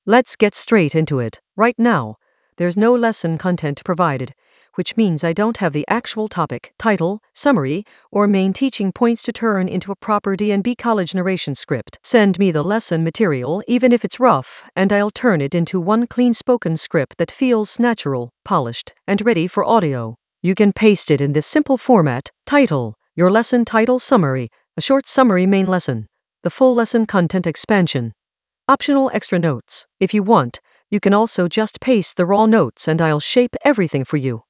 An AI-generated beginner Ableton lesson focused on Goldie FX & Atmosheres in the FX area of drum and bass production.
Narrated lesson audio
The voice track includes the tutorial plus extra teacher commentary.